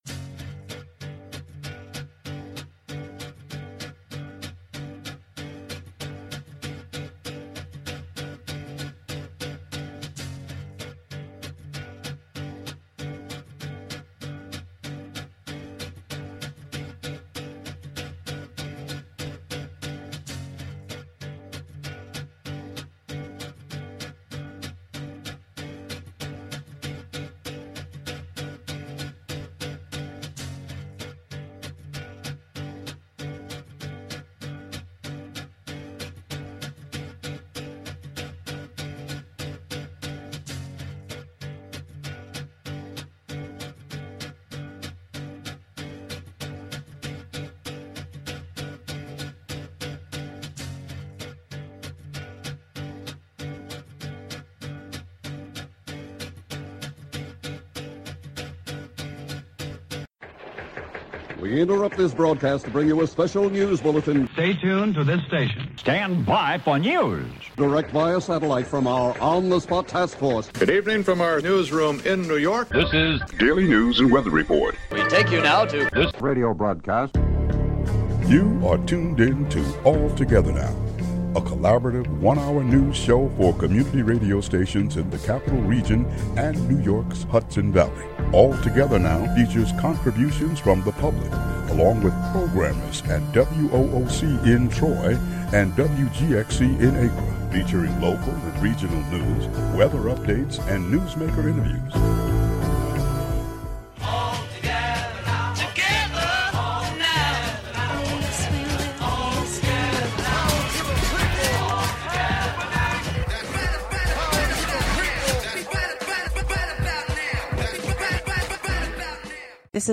Hear local news, radio news, news from the ARRL and Public News Service, and Andrew Cuomo speaking about a new broadband program, at the Hudson Library. "All Together Now!" is a new evening news show brought to you by WGXC in Greene and Columbia counties, and WOOC in Troy.